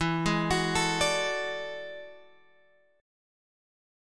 E11 chord {12 11 x 11 10 10} chord